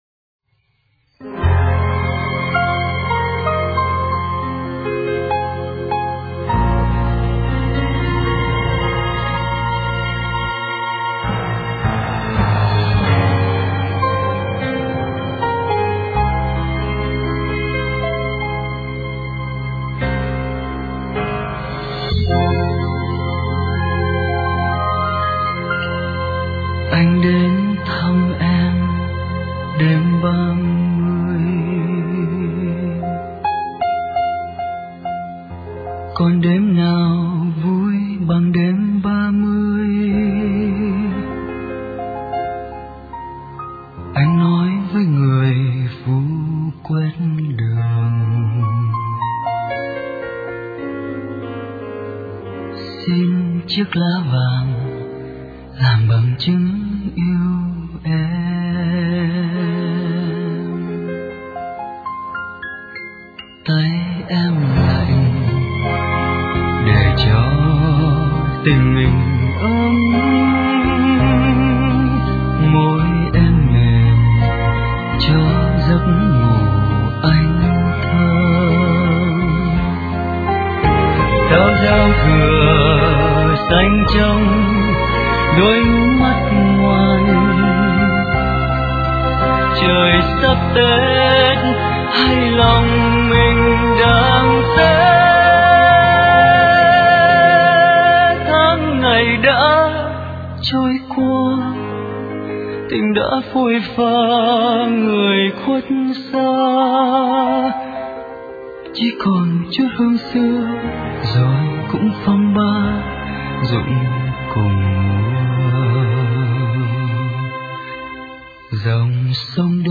* Thể loại: Xuân